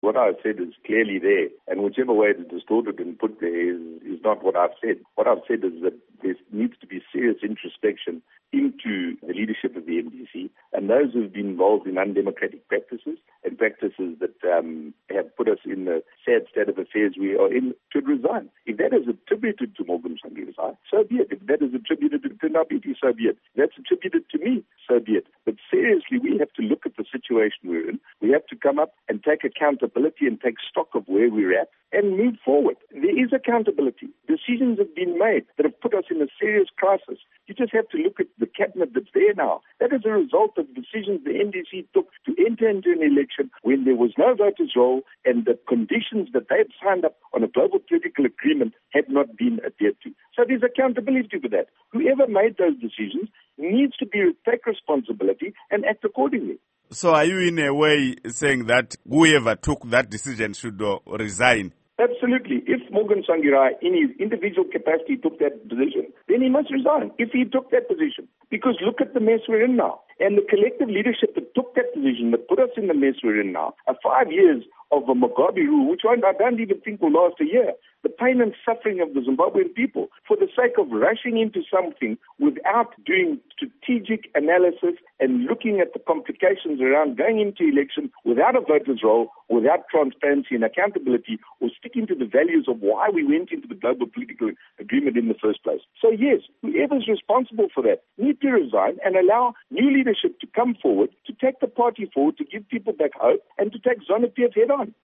Interview With Roy Bennett